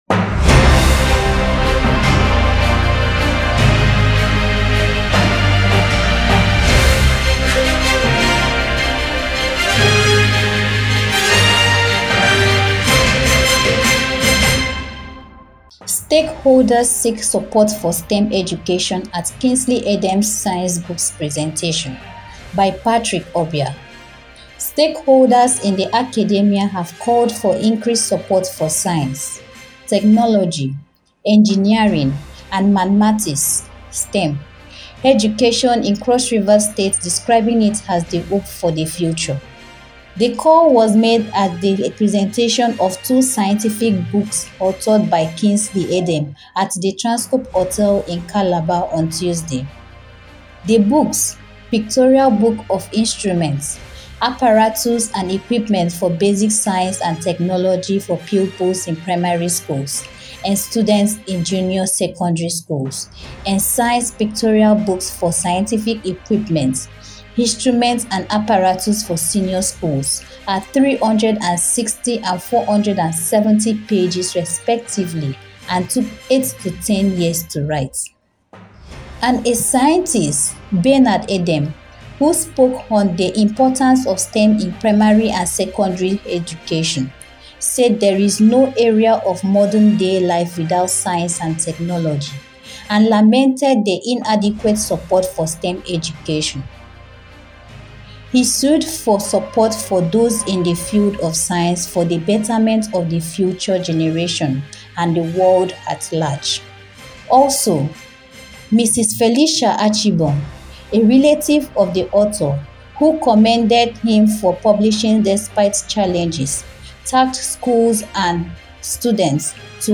Listen To The Reporter